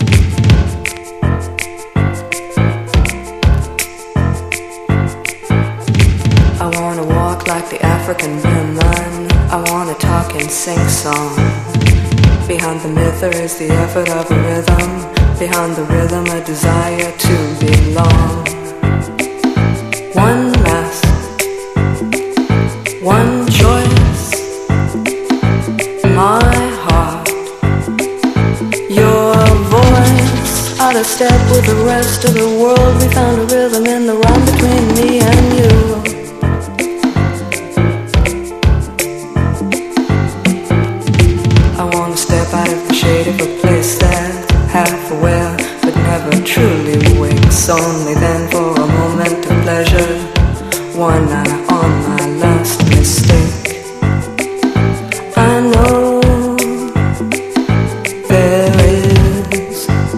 INDIE POP / UK INDIE POP / GUITAR POP (UK)
メランコリックなメロディに胸キュン必至！